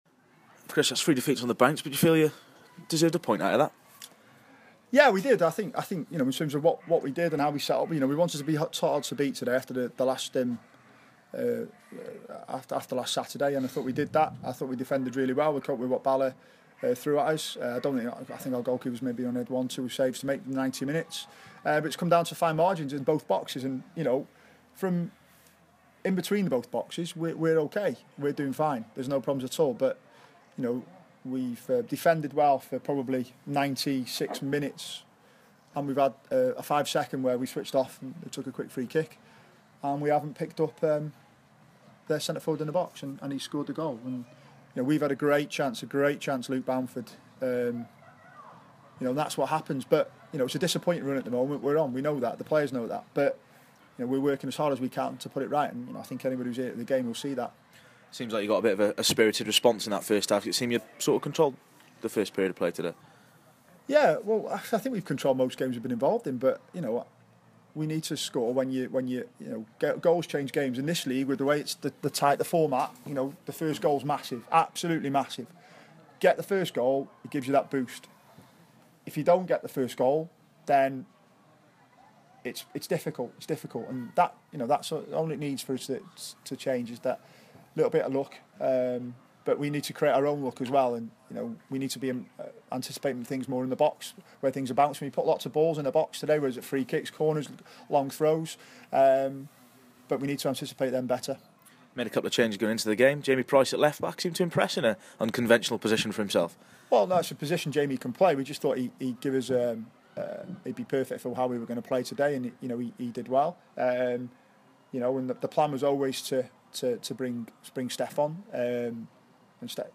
speaking after today's defeat at Maes Tegid.